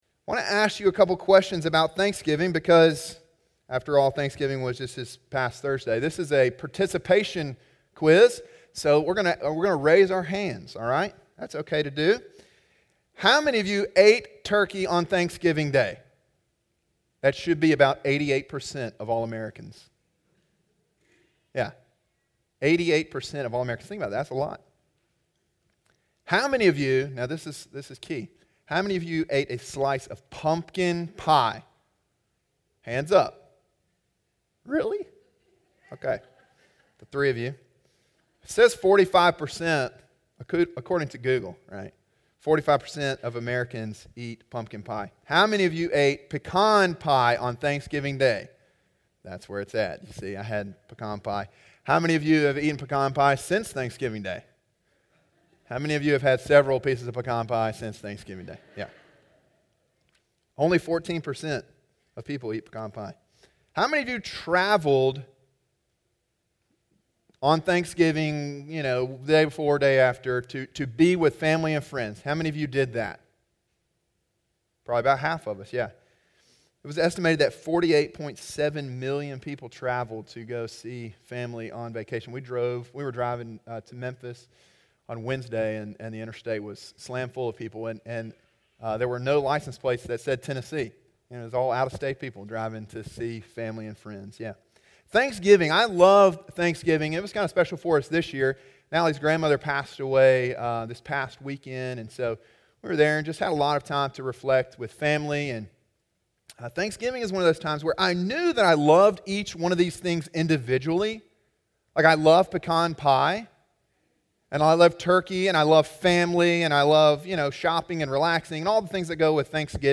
Sermon: “Come, Let Us Worship” (Psalm 95) – Calvary Baptist Church